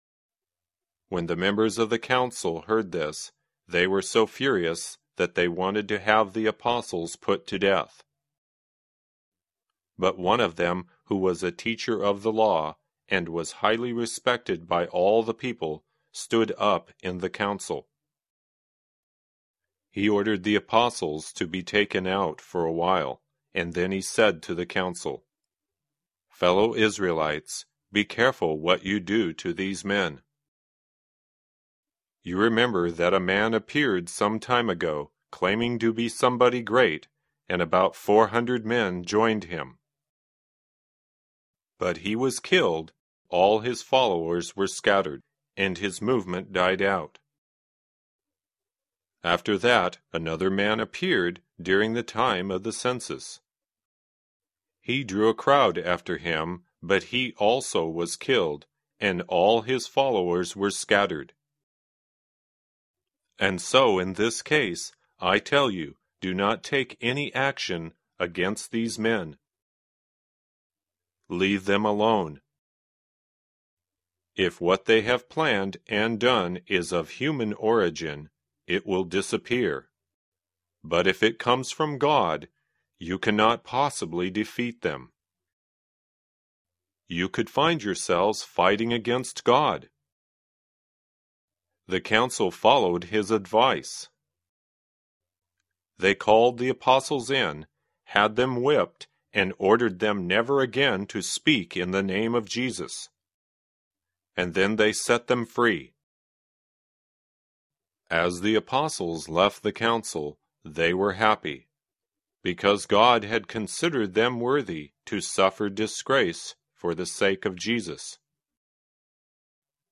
在线英语听力室免费英语课程培训-American Lesson ls12sltb的听力文件下载,免费英语课程培训,纯外教口语,初级学习-在线英语听力室